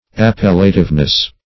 Search Result for " appellativeness" : The Collaborative International Dictionary of English v.0.48: Appellativeness \Ap*pel"la*tive*ness\, n. The quality of being appellative.